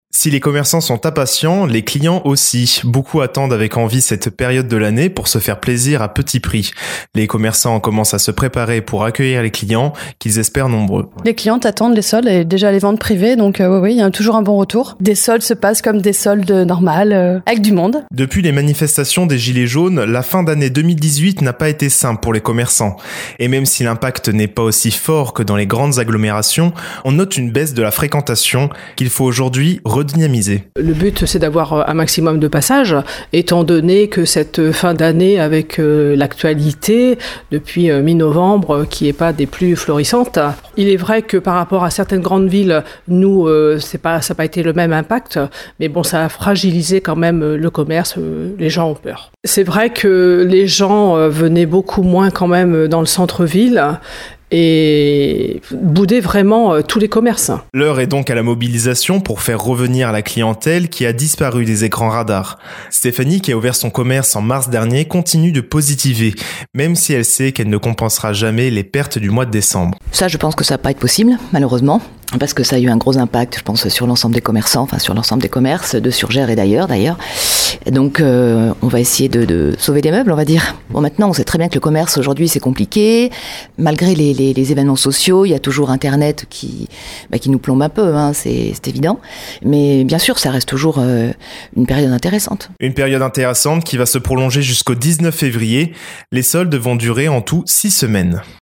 Reportage à Surgères.
soldes-reportage.mp3